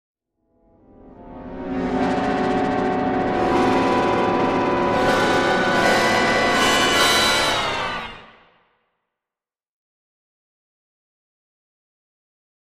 Brass Section, Accent, "The Bad End", Type 1